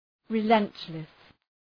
Προφορά
{rı’lentlıs}